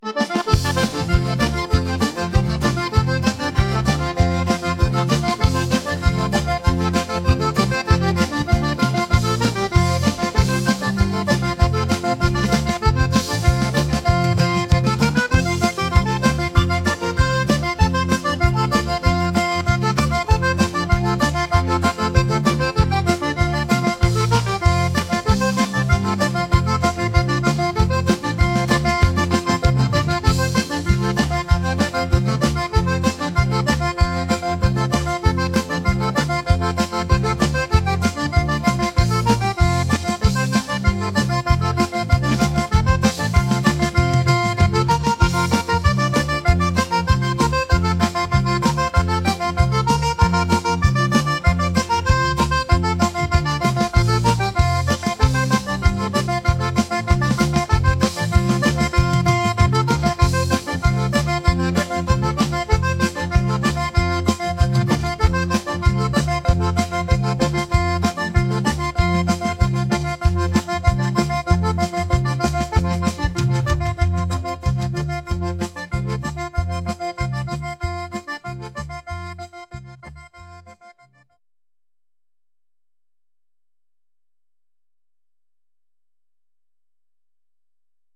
upbeat